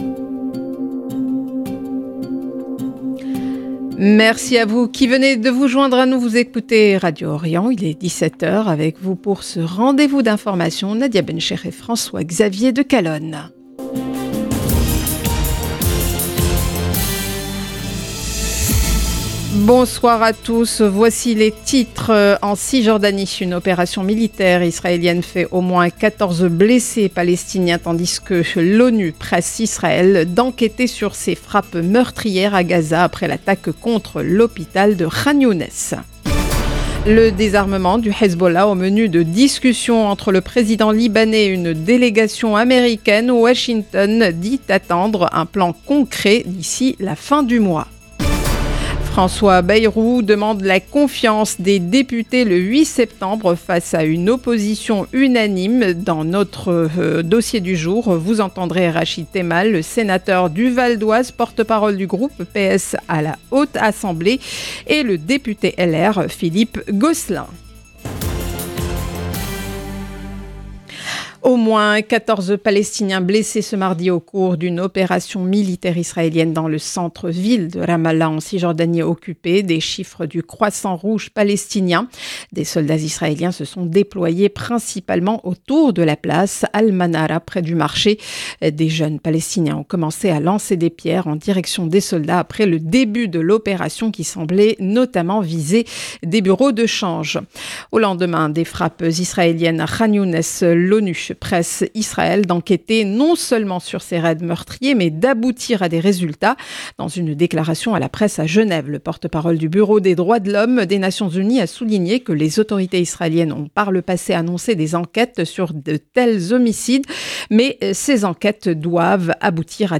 Magazine d'information de 17H du 26 août 2025